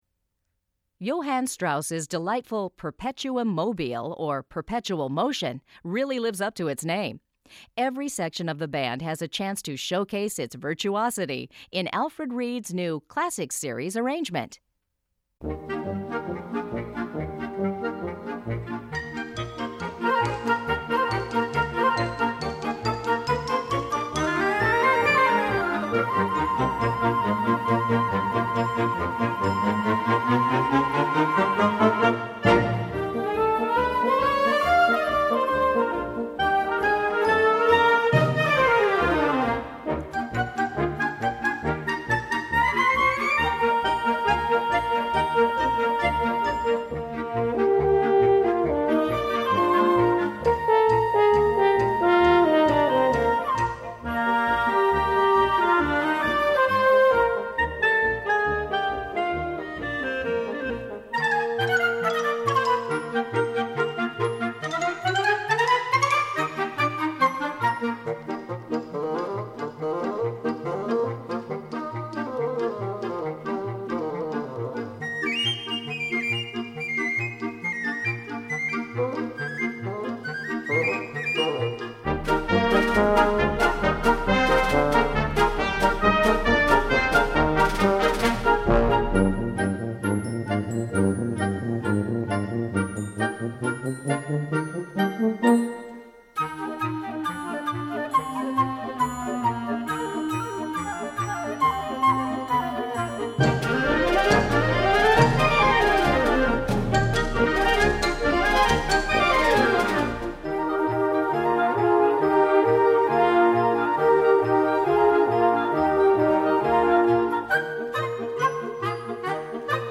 Gattung: Musikalischer Scherz
Besetzung: Blasorchester